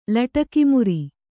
♪ laṭaki muri